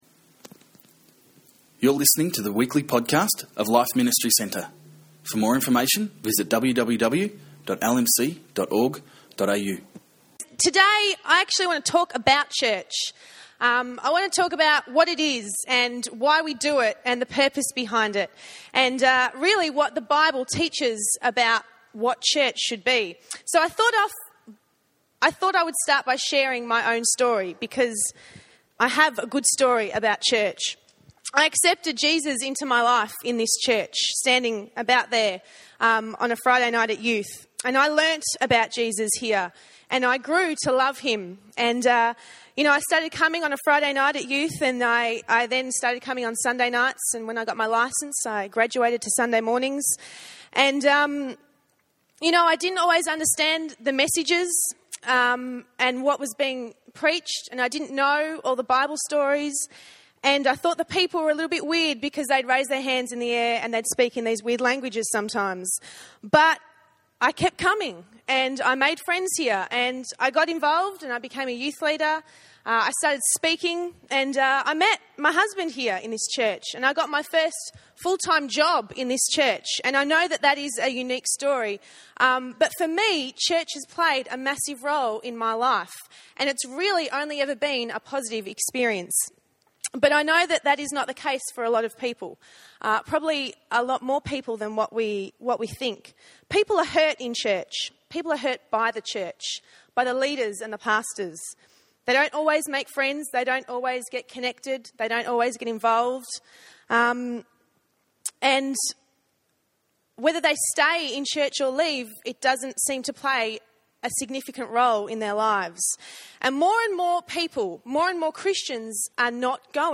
Then this is a message you need to hear!